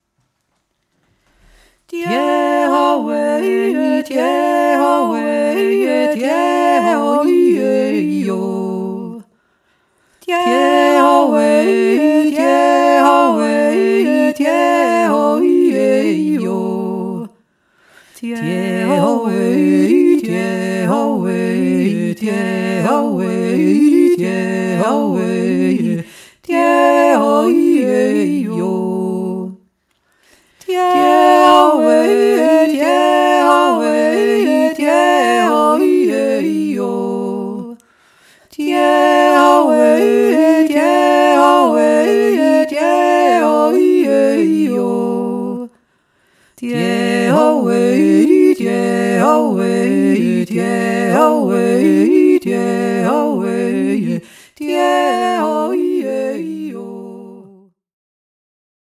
f-rchtet-euch-nicht-weihnachtsjodler.mp3